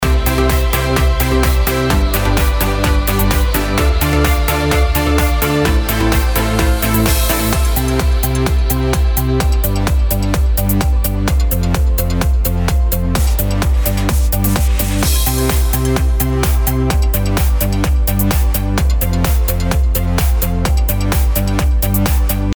Popschlagersong im aktuellen Style.
Hier kannst du kurz ins Playback reinhören.